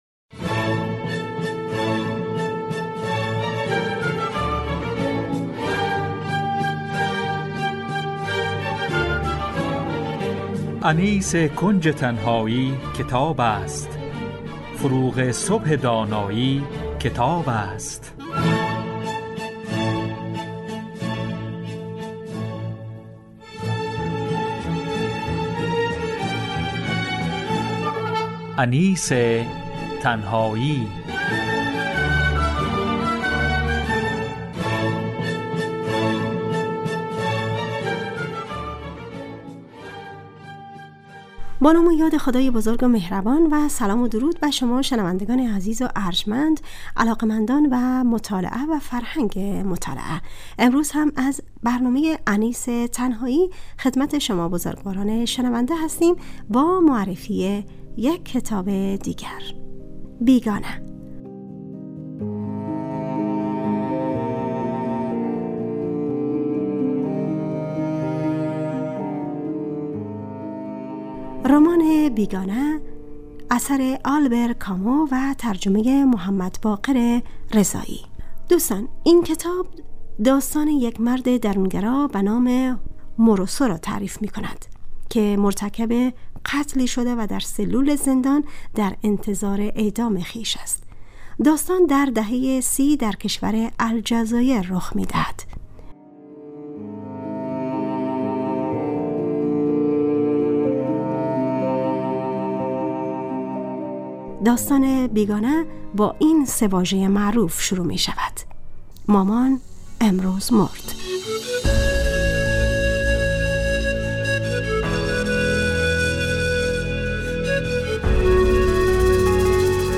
همراه با گزیده ای از سطرهایی از کتاب